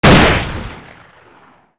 sol_reklam_link sag_reklam_link Warrock Oyun Dosyalar� Ana Sayfa > Sound > Weapons > AI_AW50f Dosya Ad� Boyutu Son D�zenleme ..
WR_fire.wav